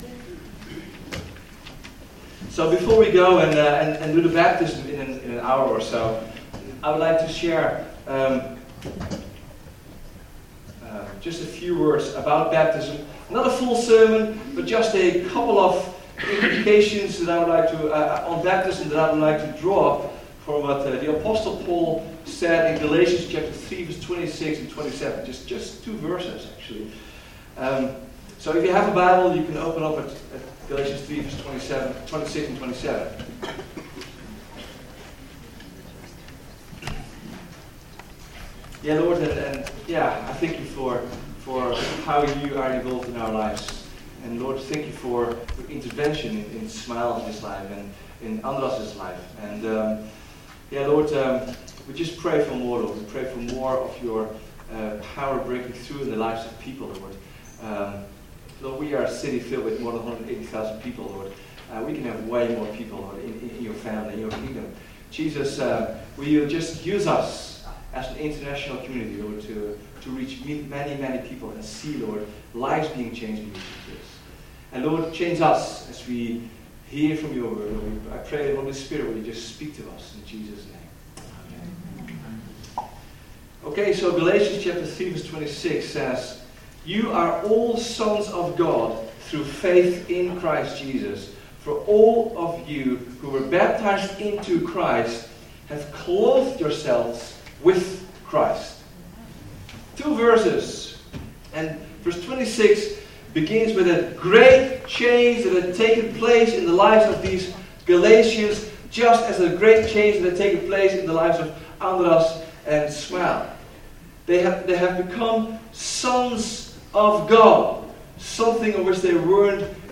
Vineyard Groningen Sermons Baptism - Clothe yourself with Christ!